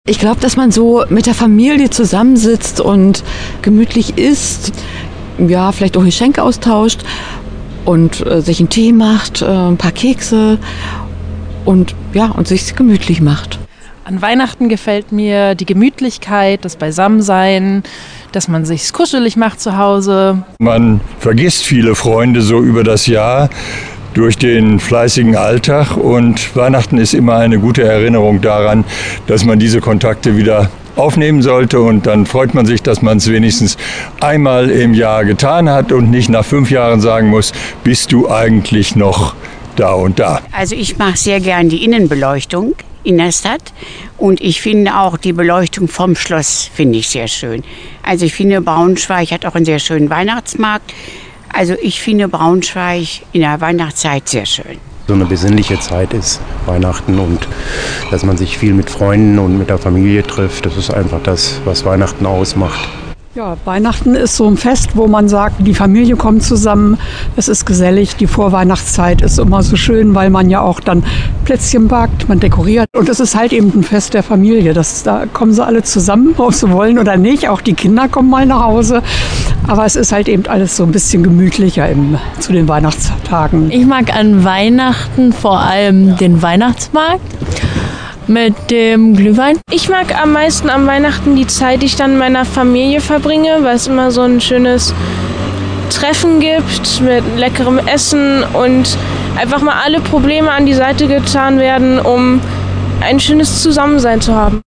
Umfrage in der Innenstadt: Was den Braunschweigern an Weihnachten besonders gefällt - Okerwelle 104.6